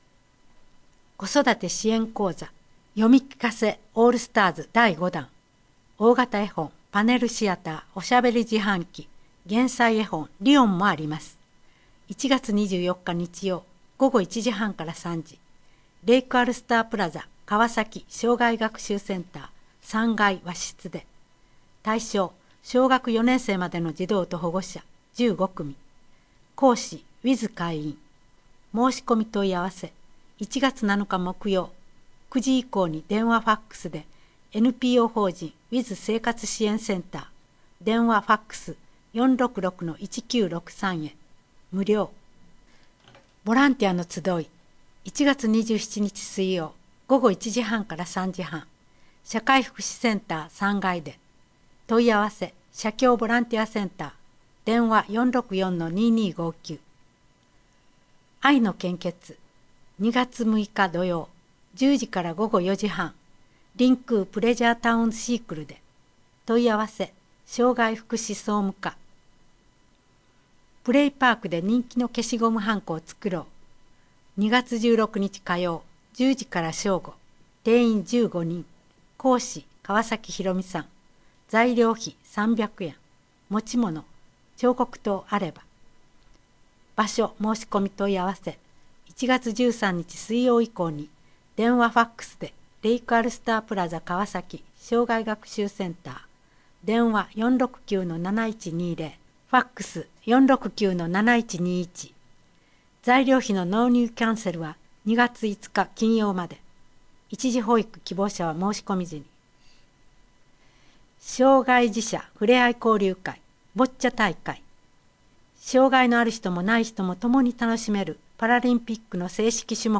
このページでは、視覚障害をお持ちの方のために泉佐野市社会福祉協議会「声のボランティア」のみなさんが朗読した広報の音声ファイルをダウンロードできます。